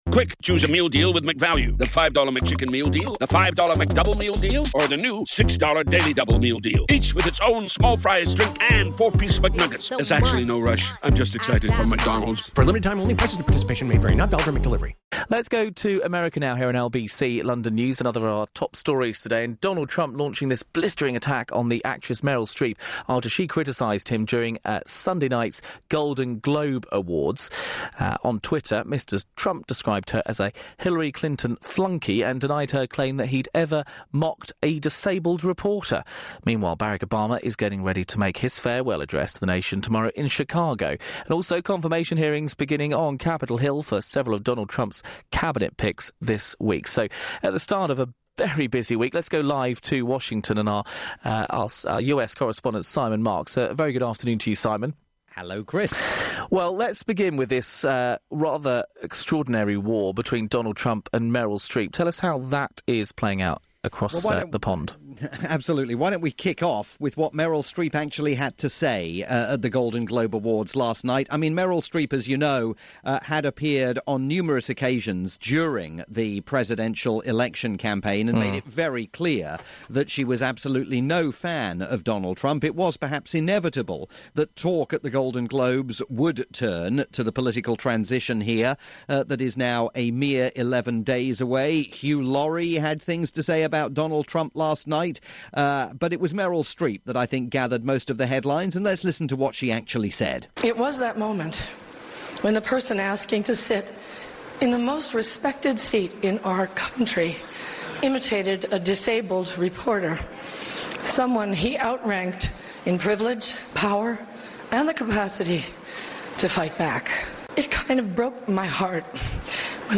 report aired on the UK's rolling news station, LBC London News.